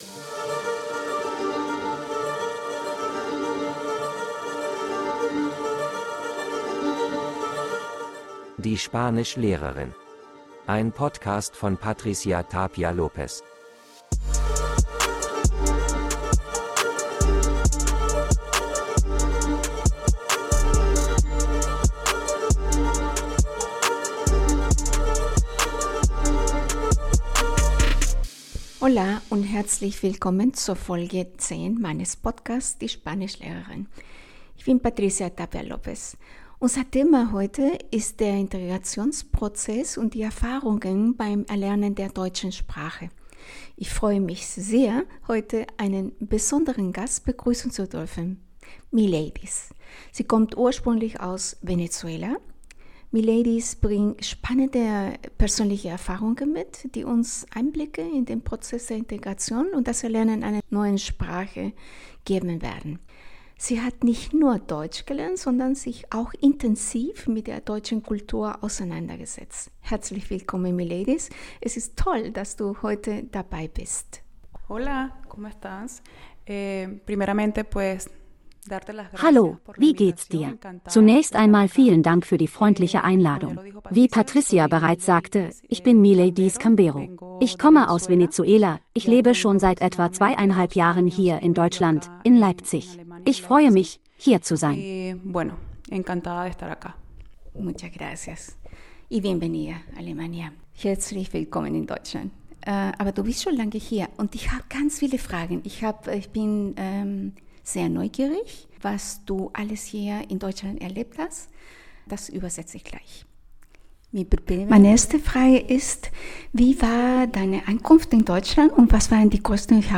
Dieses inspirierende Gespräch zeigt, wie Mut, Geduld und Kontinuität den Weg zur erfolgreichen Integration ebnen können.